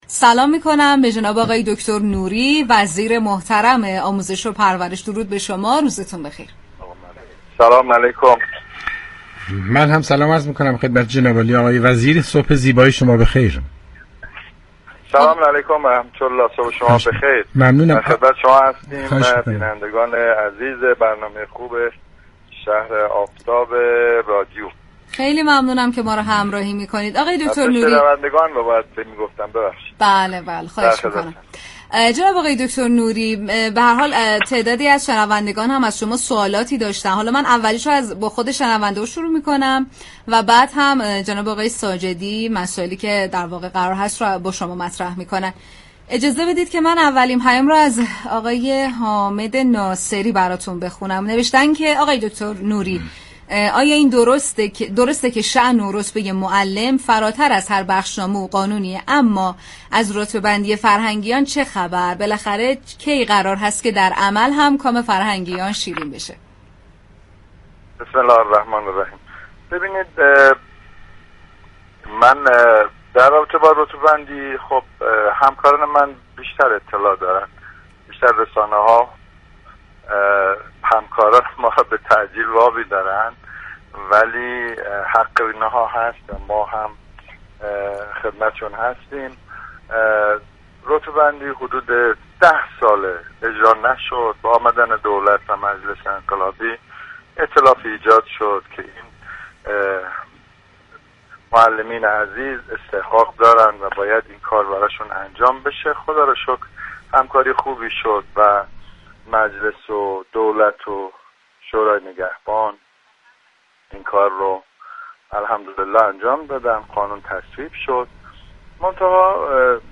به گزارش پایگاه اطلاع رسانی رادیو تهران، یوسف نوری وزیر آموزش و پرورش در گفت‌و‌گو با شهر آفتاب رادیو تهران درخصوص طرح رتبه‌ بندی معلمان‌، گفت: متاسفانه رتبه‌بندی معلمان مدت 10 سال بود كه اجرا نمی‌شد.